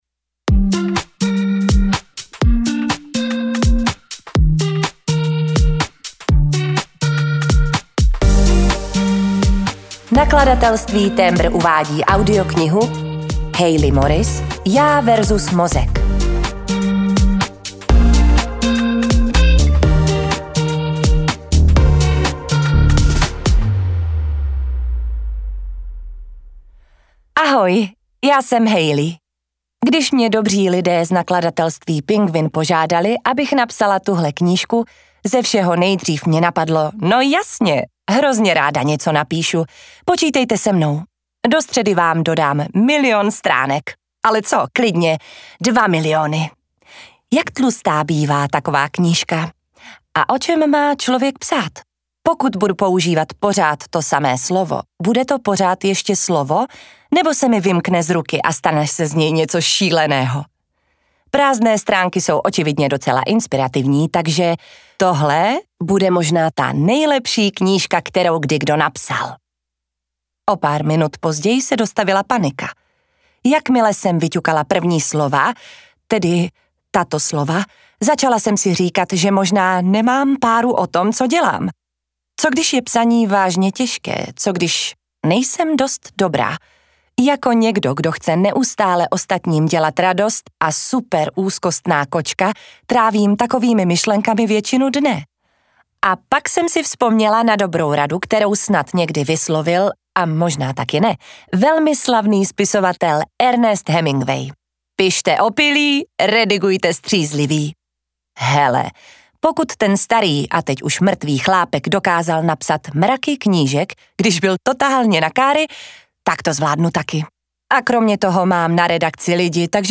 Já vs. Mozek: Taky vás vaše hlava nenechá spát? audiokniha
Ukázka z knihy